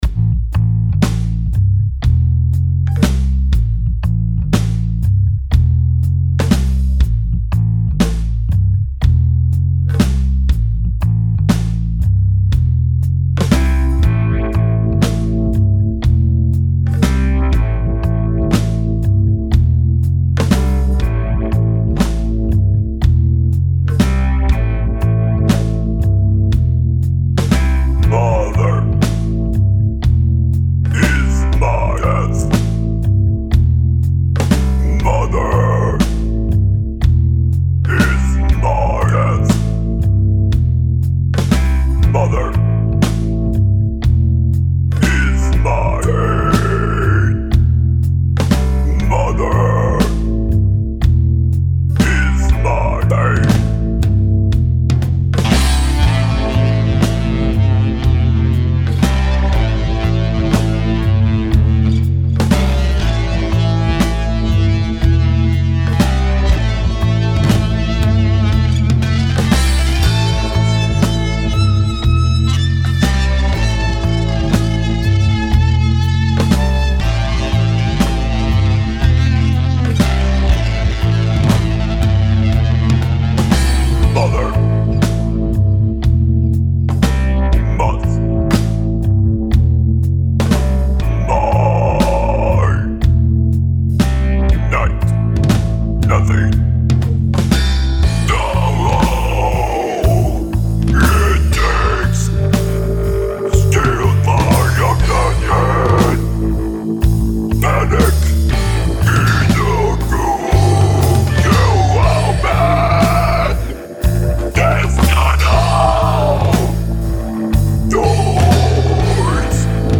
Das Stück ist noch nicht fertig abgemischt, aber man kann schon erkennen, wohin die Reise geht. Die Gitarre habe ich mit der Real Rickenbacker simuliert und kräftig in die Effektkiste gegriffen: Der Flanger aus u-he Uhbik sorgt für einen ungemein scharfen Biss. Ihm voran geht Positive Grid Bias FX (Amp und Effekt-Simulation), und am Ende der Insert-Kette produziert Sigmund von D16 Group leicht angezerrte Echos.